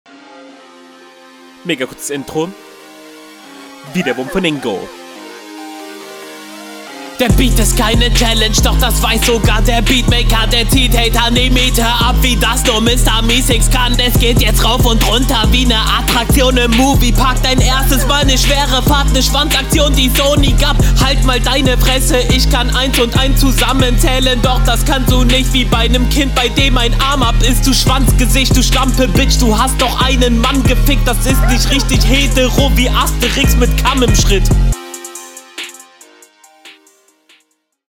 Die Audio klingt extrem Scharf im Ohr, das könnte alles geiler gemischt sein Ist textlich …